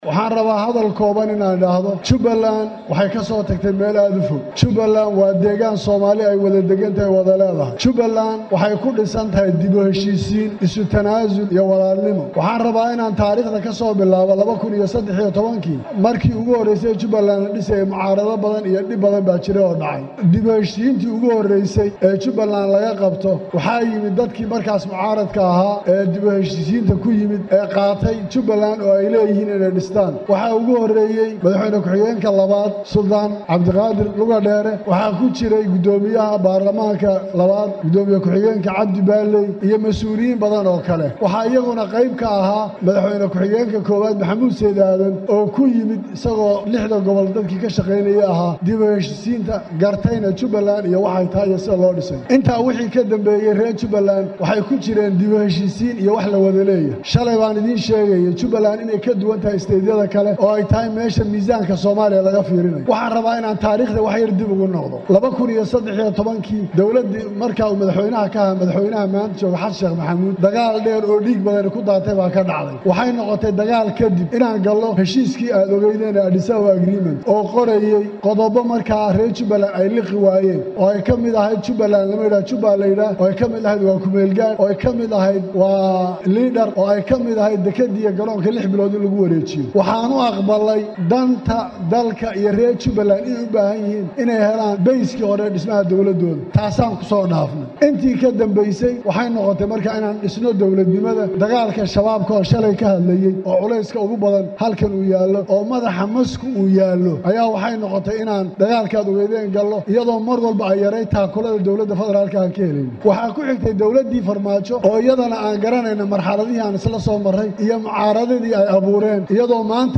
Intaas ka dib, madaxweynaha dib loo doortay ee Jubbaland Axmad Madoobe ayaa Khudbad uu jeediyay markii loo xaqiijiyay inuu ku guuleystay doorashadii.